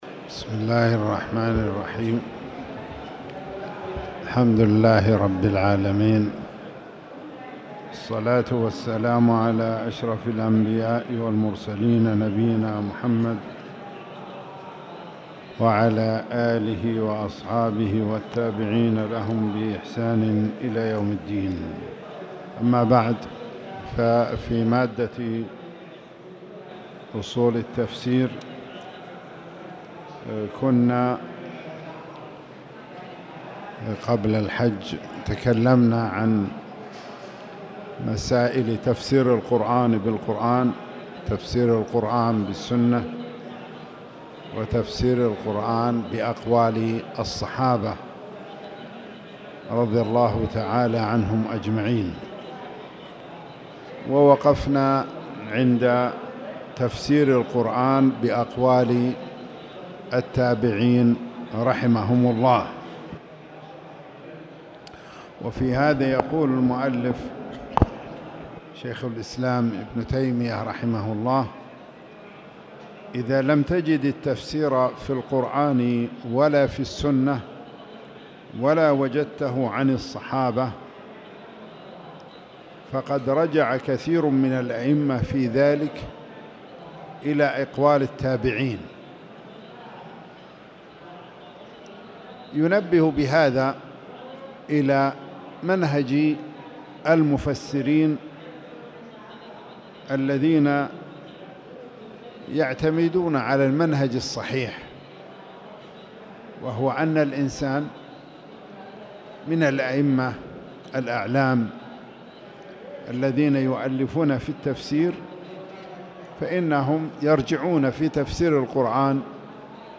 تاريخ النشر ١ محرم ١٤٣٨ هـ المكان: المسجد الحرام الشيخ